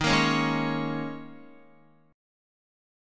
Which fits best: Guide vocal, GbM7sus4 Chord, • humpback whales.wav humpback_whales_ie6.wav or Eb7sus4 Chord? Eb7sus4 Chord